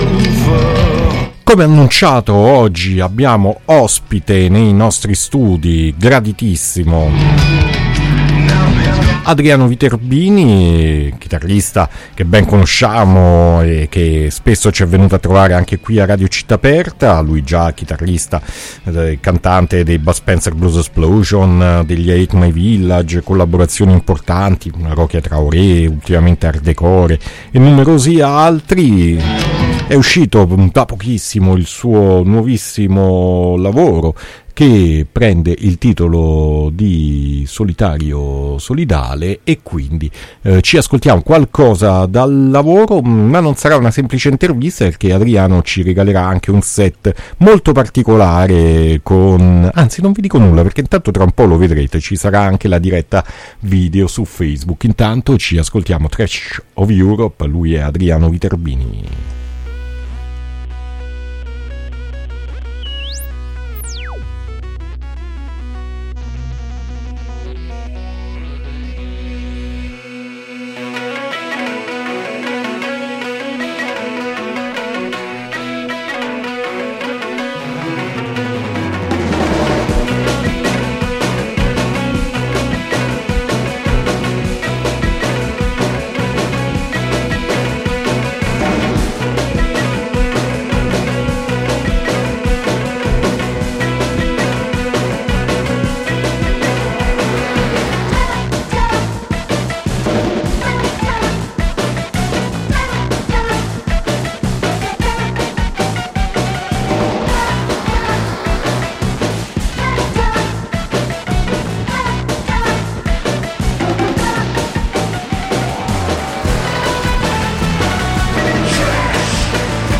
un lavoro di contaminazione afro psichedelico blues
suonando anche alcuni brani live